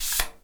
pgs/Assets/Audio/Miscellaneous/Spray Bottle/spray_bottle_14.wav at master
spray_bottle_14.wav